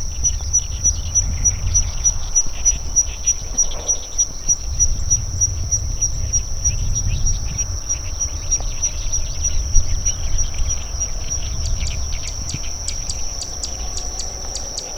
• セッカ（ヒタキ科）